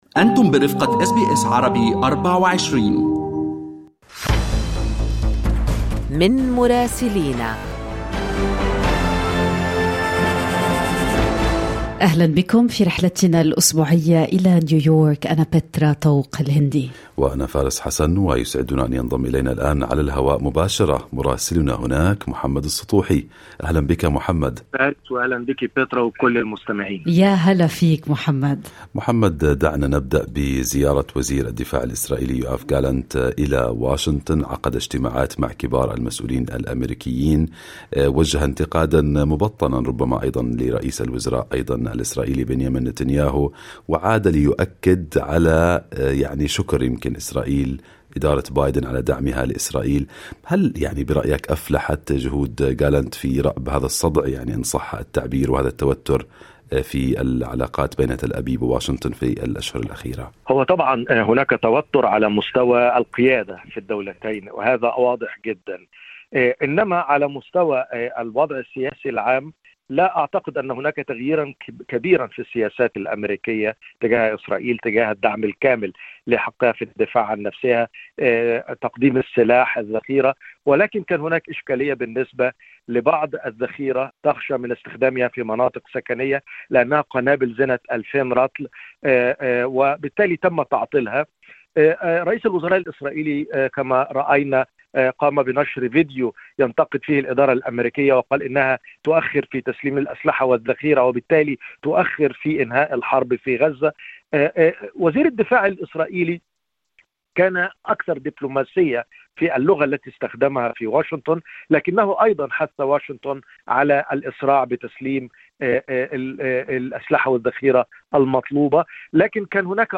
وزير الدفاع الإسرائيلي يوآف غالانت، يختتم زيارته للولايات المتحدة، ويقول إن اجتماعاته مع كبار المسؤولين في واشنطن مؤكداً تحقيق تقدم في القضايا المتعلقة بشحنات الأسلحة إلى إسرائيل والمناظرة الرئاسية الأولى على بعد ساعات. ناقشنا هذه المواضيع مع مراسلنا في نيويورك